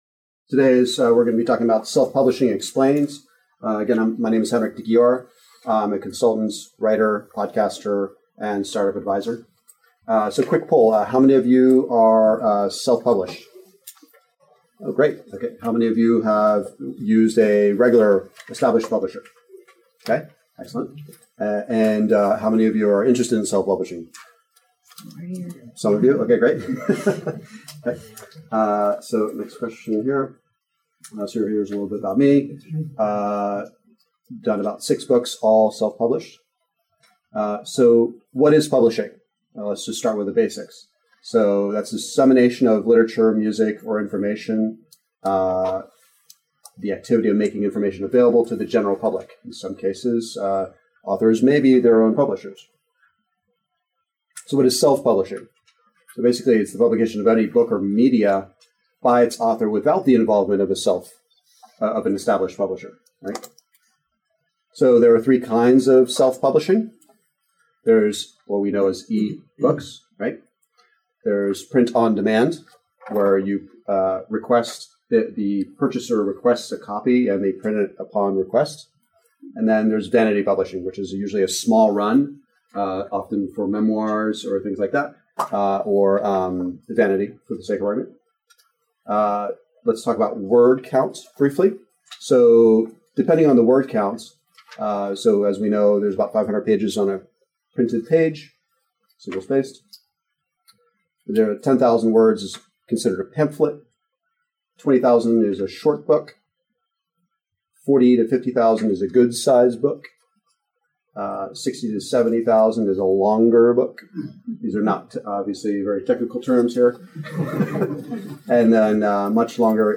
Here is an audio recording of this presentation